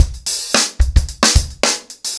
TupidCow-110BPM.45.wav